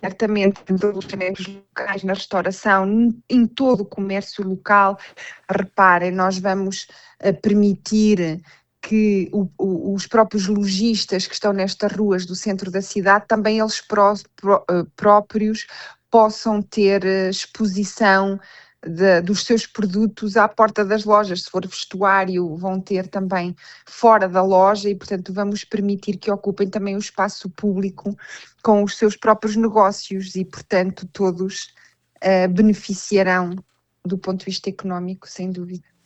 Entre 28 de abril e 3 de maio são esperados milhares de visitantes na cidade. De acordo com a autarca, a restauração e os alojamentos estão praticamente esgotados neste período, refletindo um impacto económico significativo: